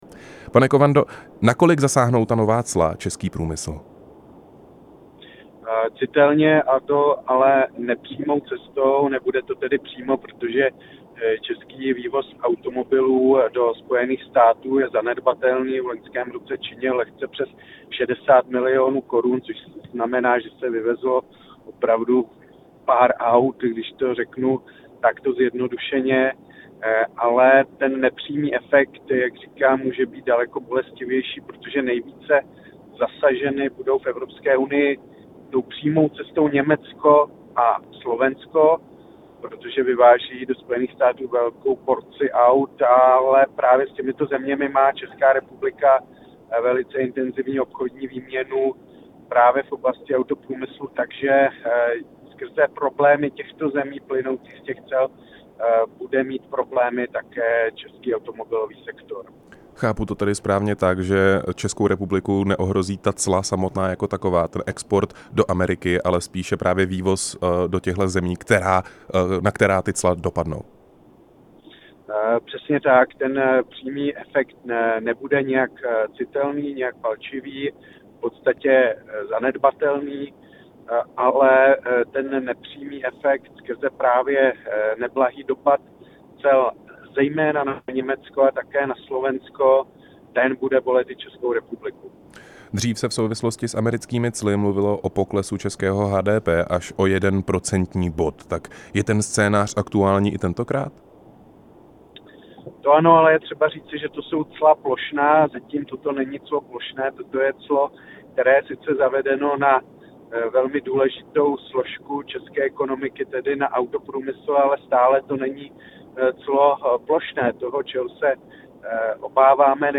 Rozhovor s ekonomem Lukášem Kovandou